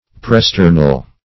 -- Pre*ster"nal , a. [1913 Webster]
presternal.mp3